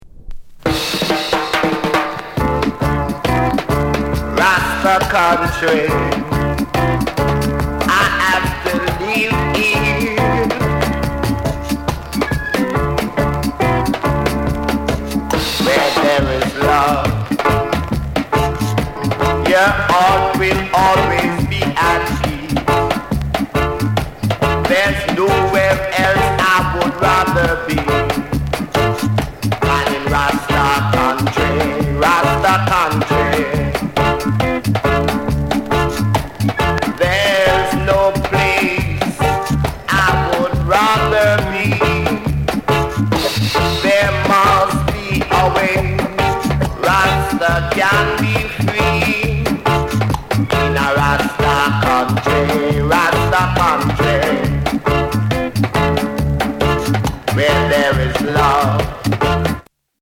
ROOTS